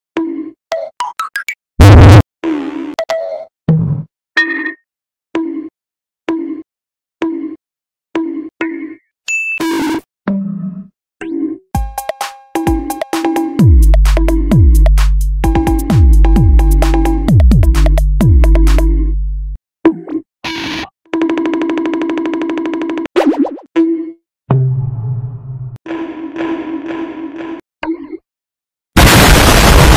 دانلود آهنگ چماق 2 از افکت صوتی انسان و موجودات زنده
دانلود صدای چماق 2 از ساعد نیوز با لینک مستقیم و کیفیت بالا
جلوه های صوتی